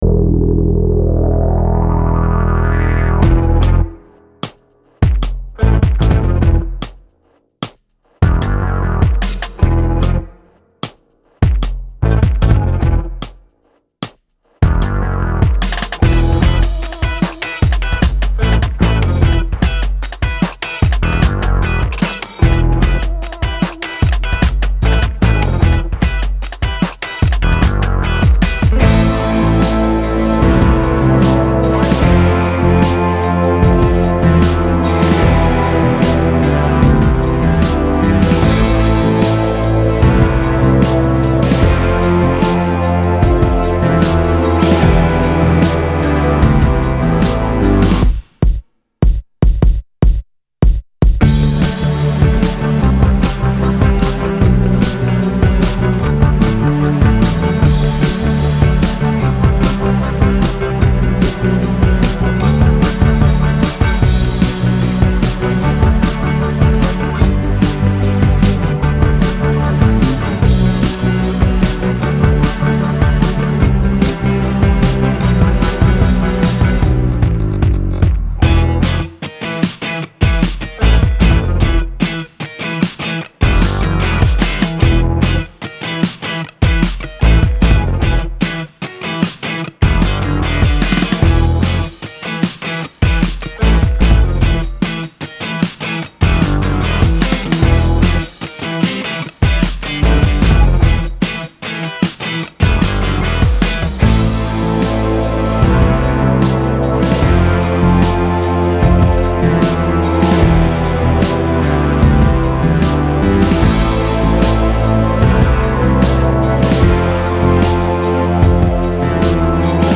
Genre: Alternative, Alt-Pop, Indie